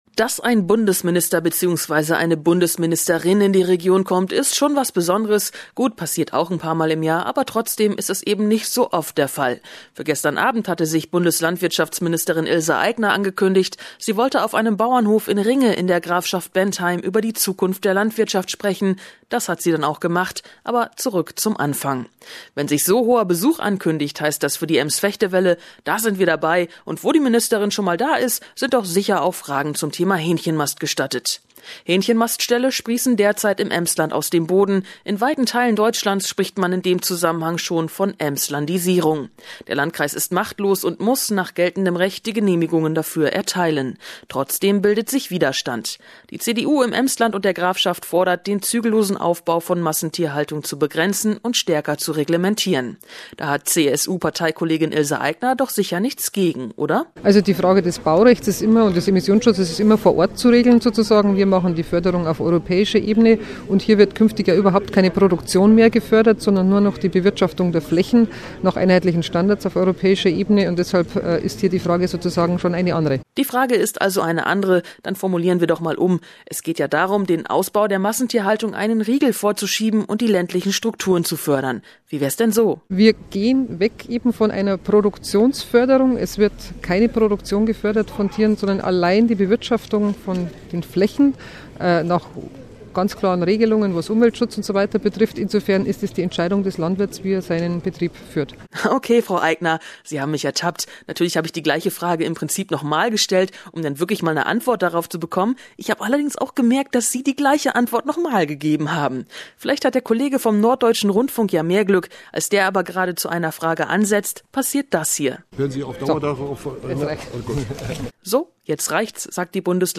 Erfahrungsbericht